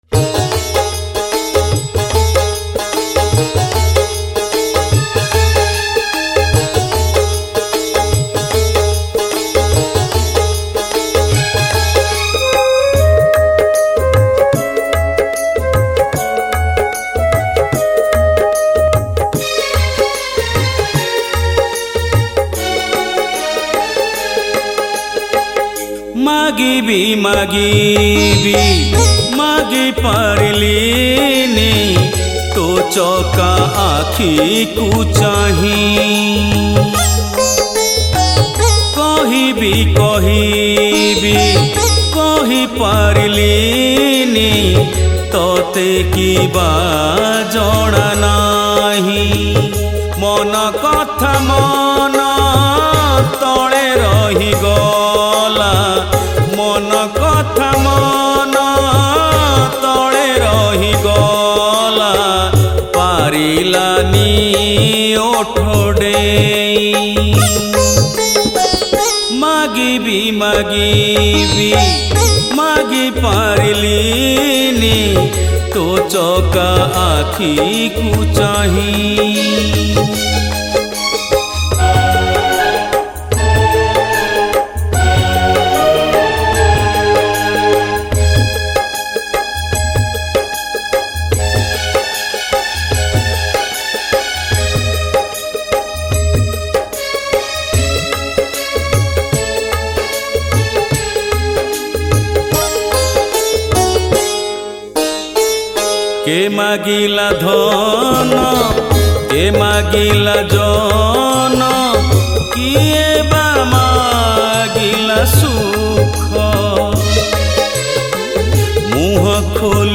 Odia Bhakti Song